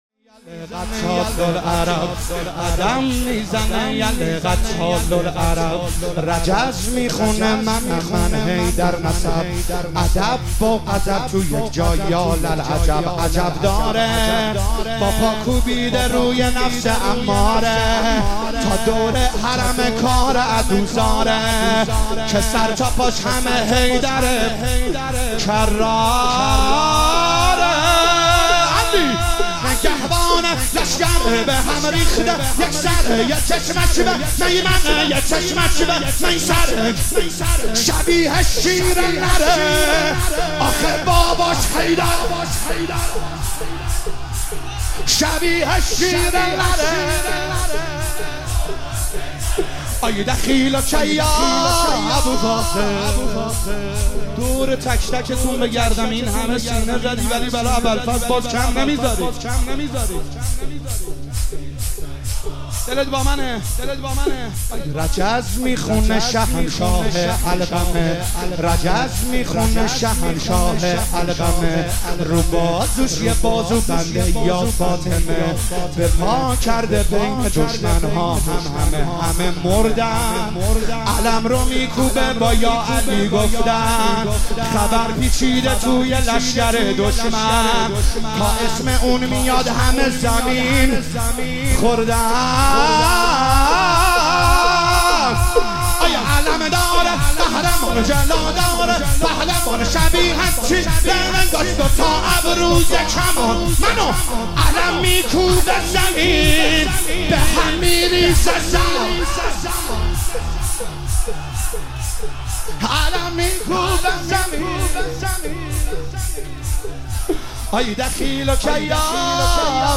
تک نوحه